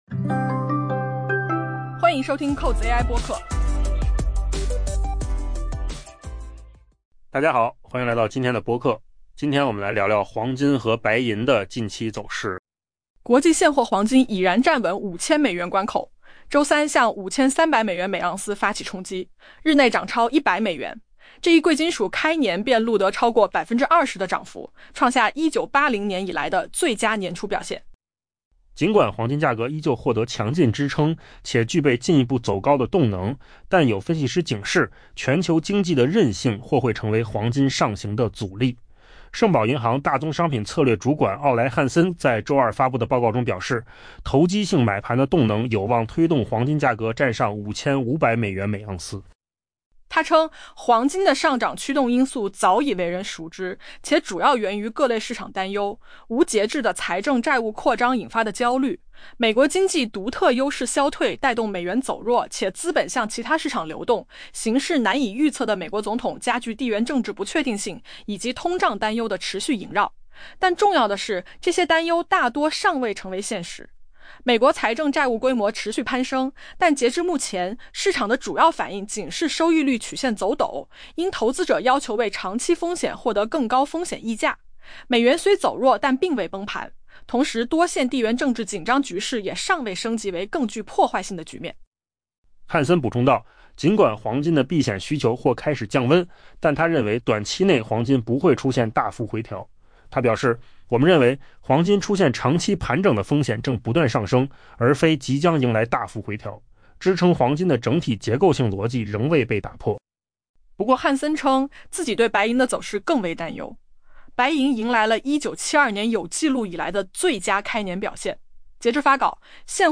【文章来源：金十数据】AI播客：换个方
AI 播客：换个方式听新闻 下载 mp3 音频由扣子空间生成 国际现货黄金已然站稳 5000 美元关口，周三向 5300 美元/盎司发起冲击，日内涨超 100 美元。